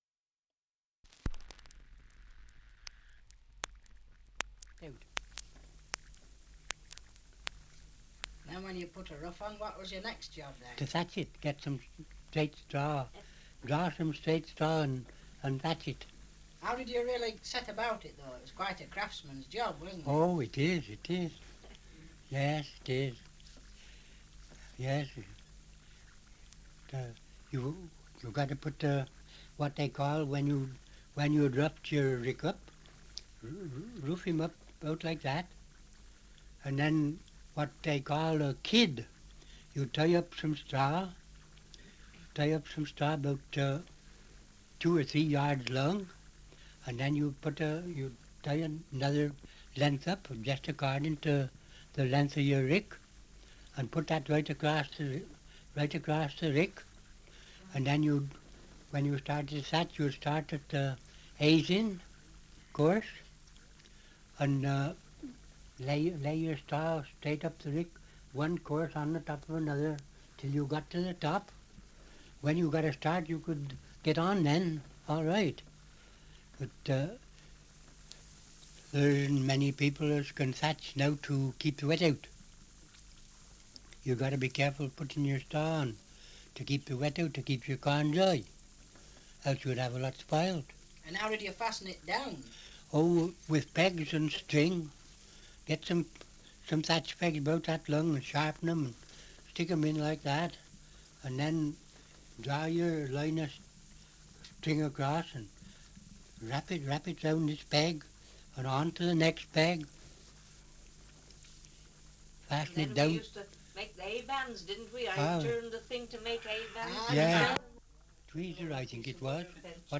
2 - Survey of English Dialects recording in Kinlet, Shropshire
78 r.p.m., cellulose nitrate on aluminium